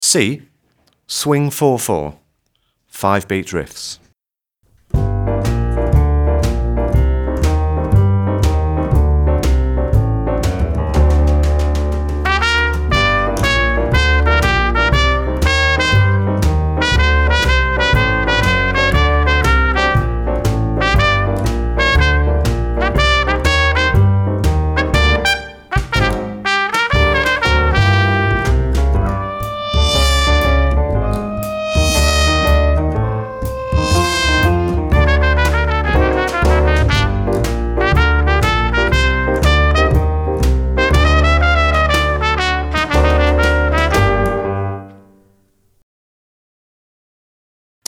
Compound Step C (Swing)